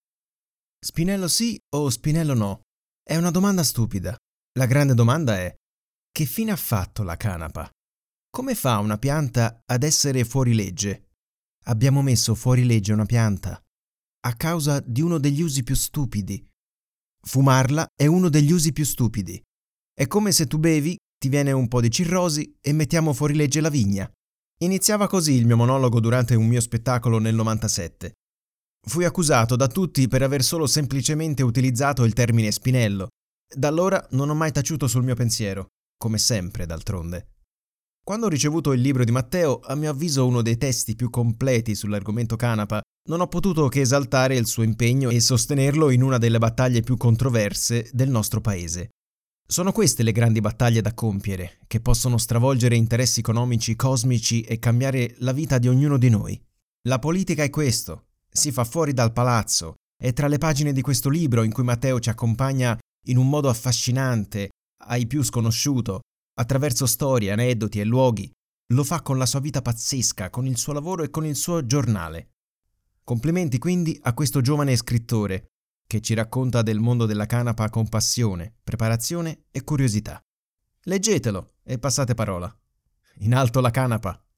Italian male voice, Voce italiana, italian speaker, dubbing, doppiaggio italiano, e-learning italiano.
Sprechprobe: Sonstiges (Muttersprache):
My voice is clear, deep and i can change it as you want for commercials, e-learning, cartoon and corporate.